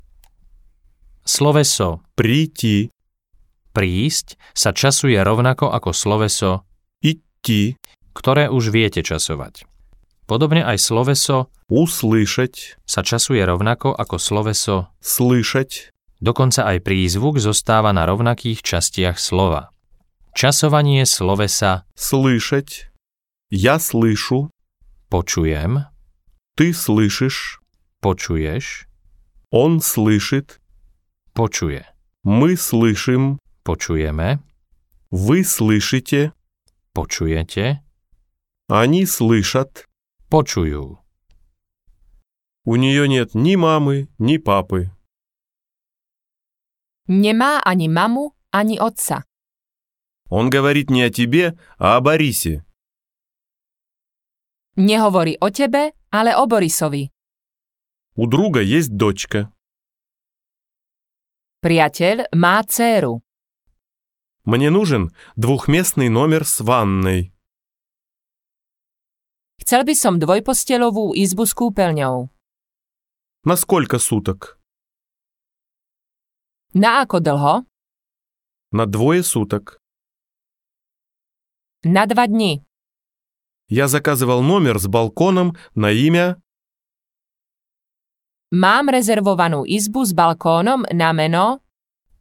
Ruština do ucha audiokniha